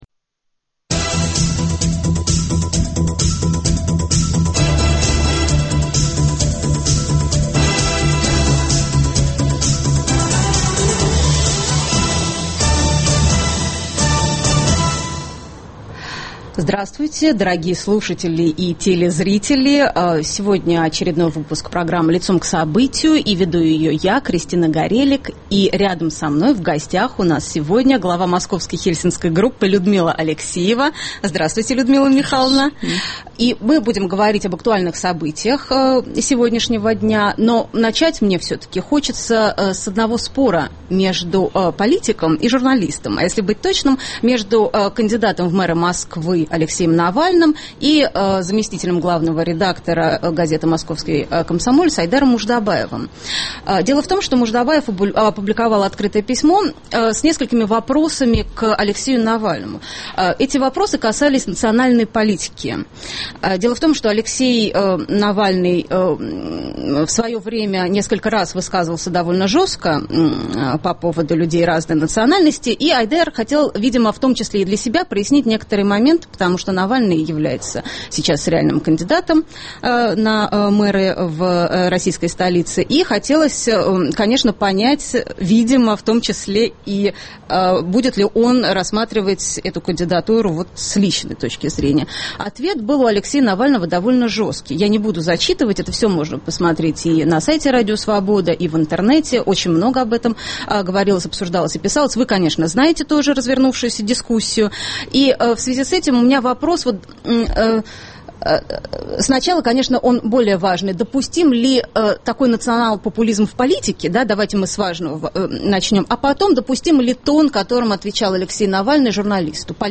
Разговор с ней в прямом эфире - об актуальных событиях дня.